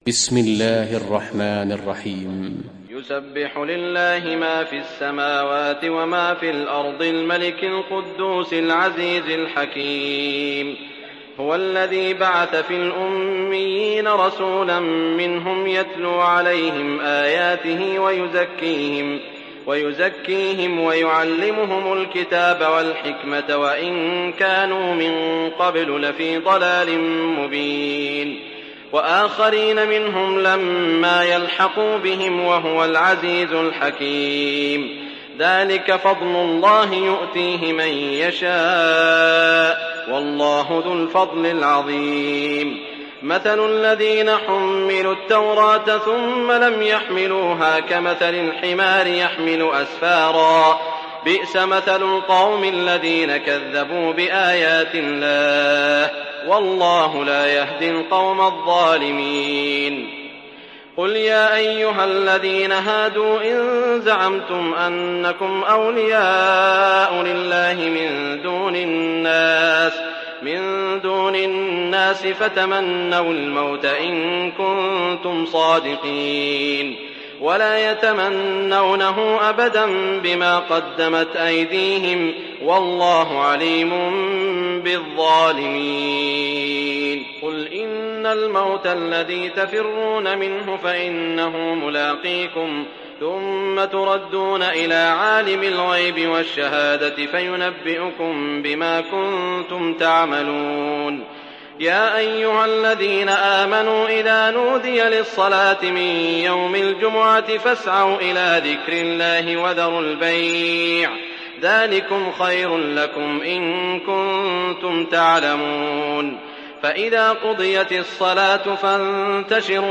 دانلود سوره الجمعه mp3 سعود الشريم روایت حفص از عاصم, قرآن را دانلود کنید و گوش کن mp3 ، لینک مستقیم کامل